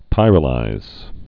(pīrə-līz)